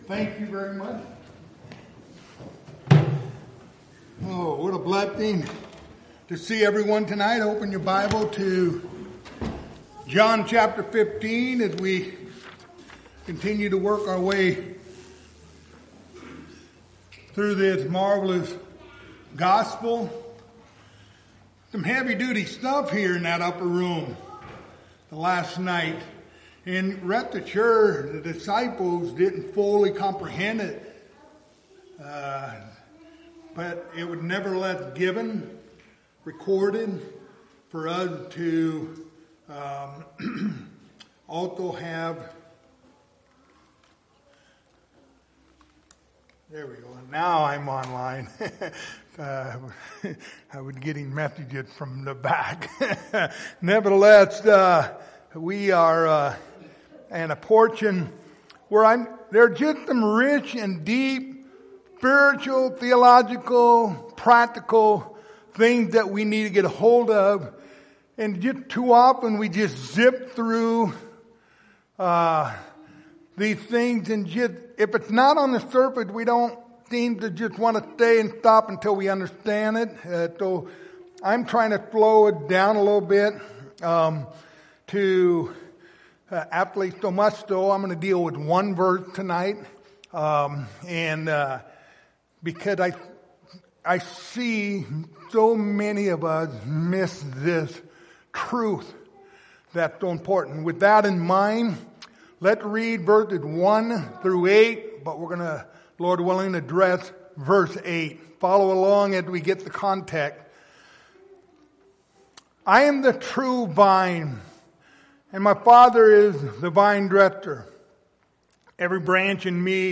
Passage: John 15:8 Service Type: Wednesday Evening